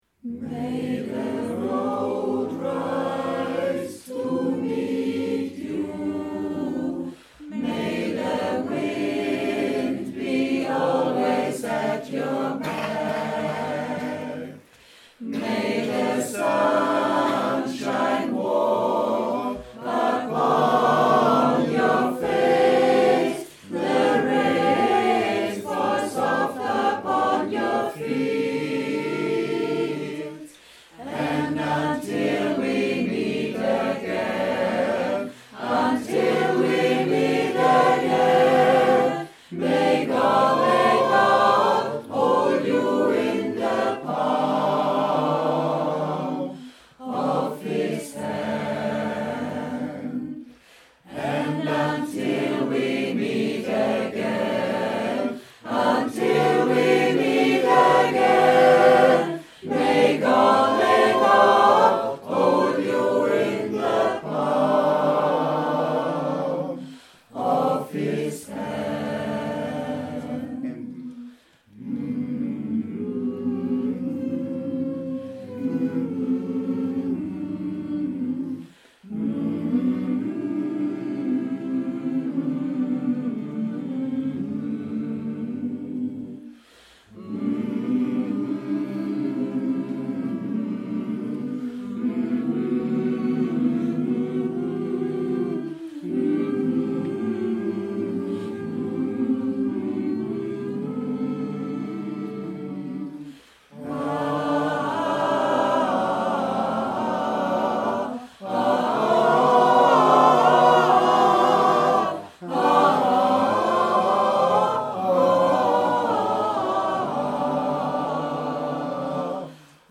XANGSMEIEREI-Probe 03.10.2016